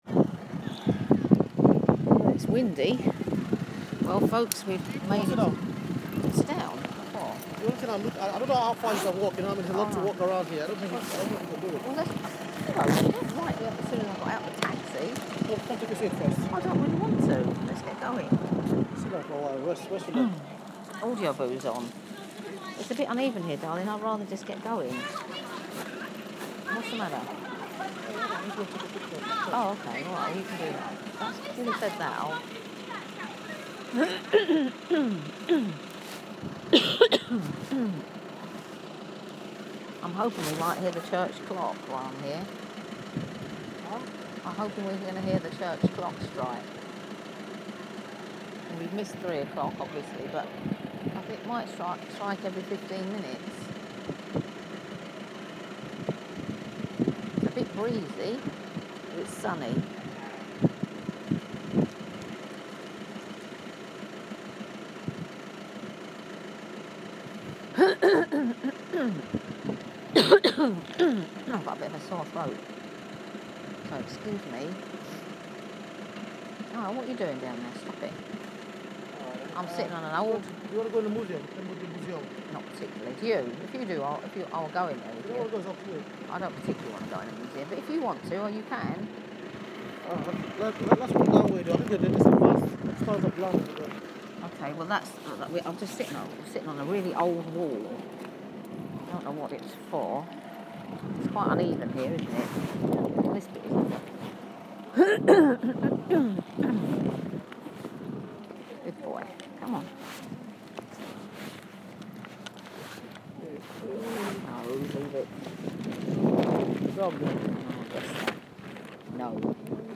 A walk around the historic town of Rye